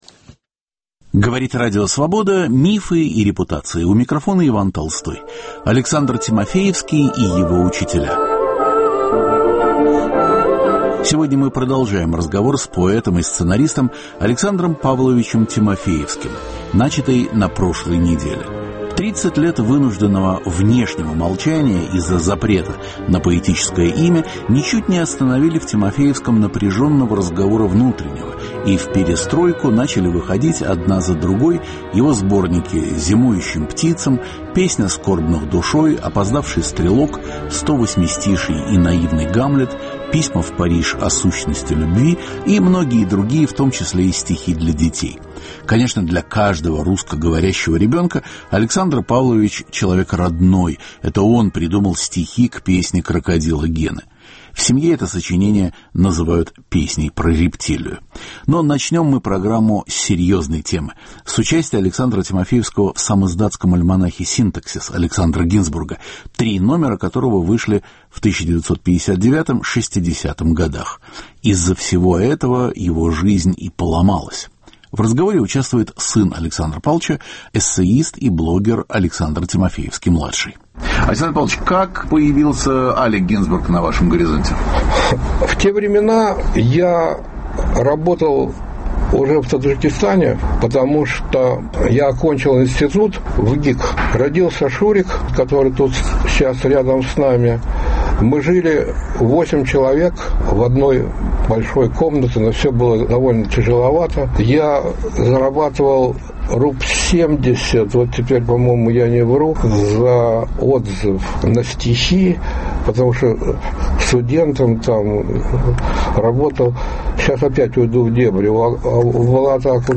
Беседа с поэтом и сценаристом Александром Павловичем Тимофеевским: от довоенного дества под Изюмом, через блокаду Ленинграда, участие в самиздатском альманахе "Синтаксис" к песне Крокодила Гены. В беседе принимает участие сын Александр Тимофеевский-младший.